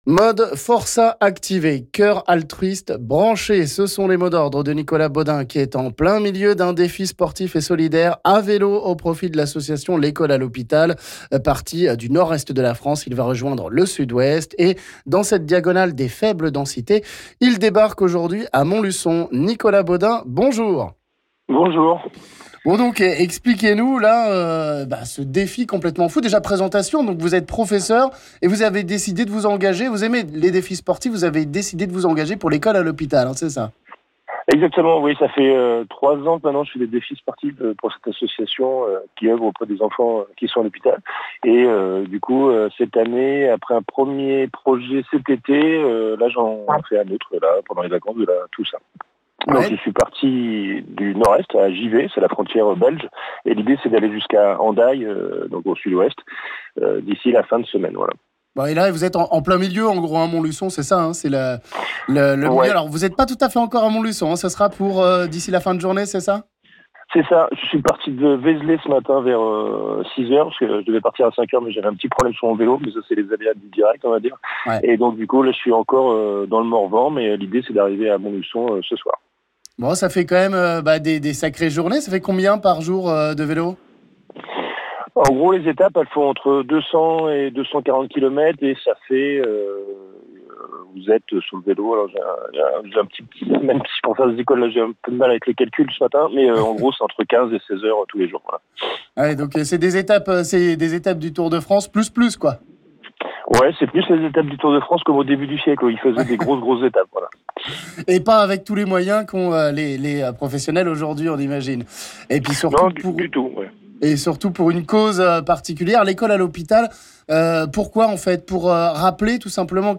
en direct sur RMB ce matin